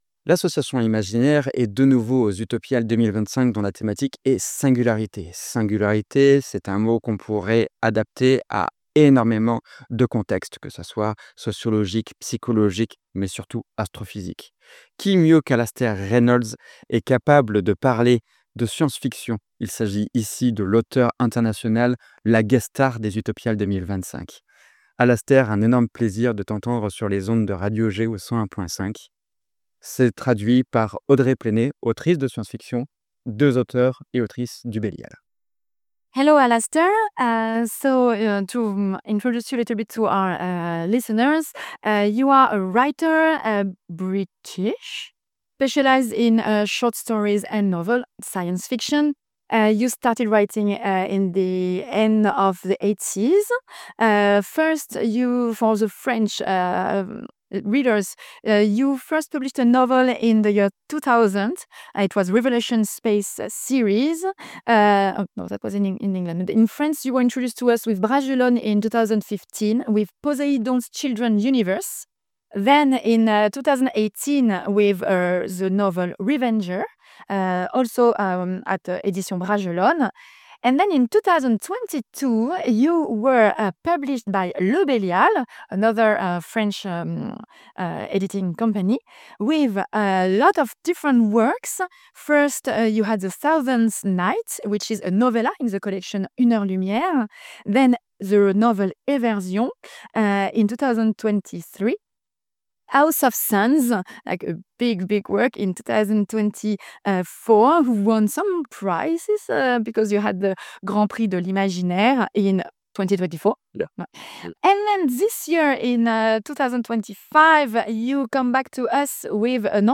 Lors des Utopiales 2025, nous avons eu la chance de réaliser une interview d'Alastair Reynolds.